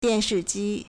电视机 (電視機) diànshìjī
dian4shi4ji1.mp3